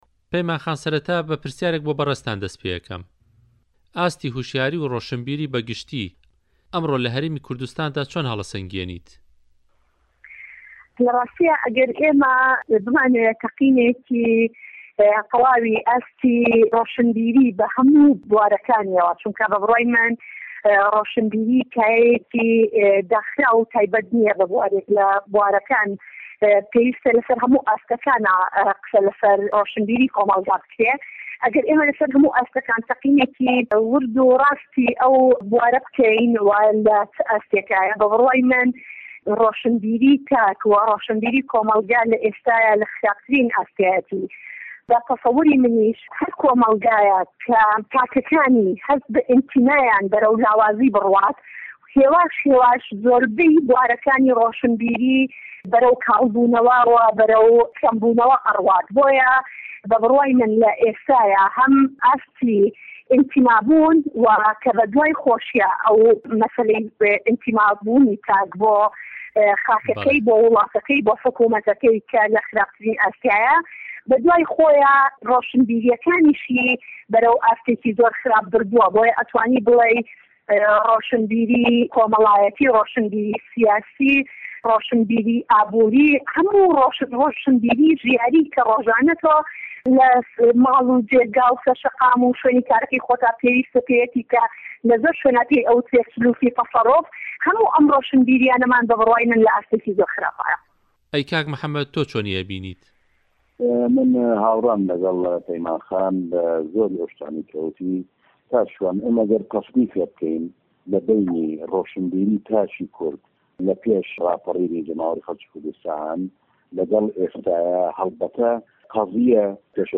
مێزگرد: ڕۆشنبیری له‌ هه‌رێمی کوردسـتانی عێراق